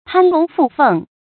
注音：ㄆㄢ ㄌㄨㄙˊ ㄈㄨˋ ㄈㄥˋ
攀龍附鳳的讀法